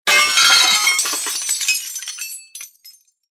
Plate Smashing Sound Effect
Hear the realistic sound of a ceramic kitchen plate smashing on a hard surface. Experience the sharp noise of glass shattering on tiles.
Plate-smashing-sound-effect.mp3